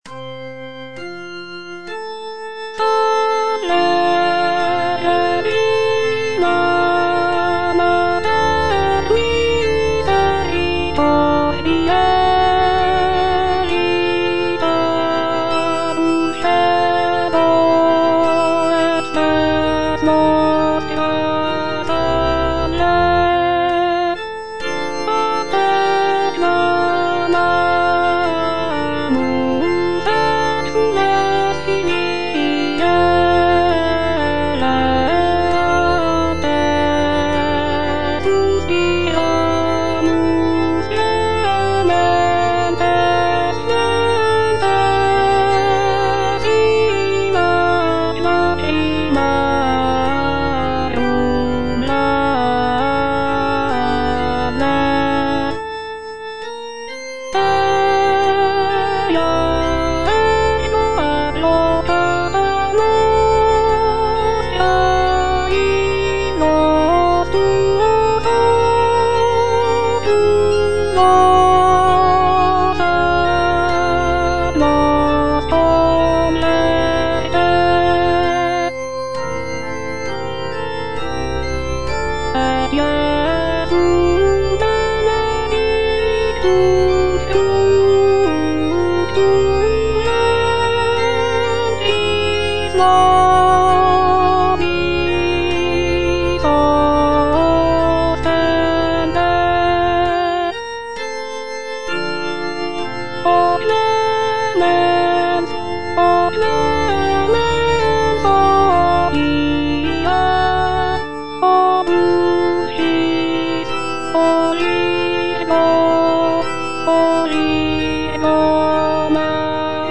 G. FAURÉ (ARR. H. BÜSSER) - SALVE REGINA Alto (Voice with metronome) Ads stop:
"Salve Regina" is a choral work composed by Gabriel Fauré, arranged by Henri Büsser. This piece is a setting of the traditional Latin hymn to the Virgin Mary, known for its hauntingly beautiful melodies and rich harmonies. Fauré's delicate and refined style is evident in this arrangement, with lush textures and intricate counterpoint.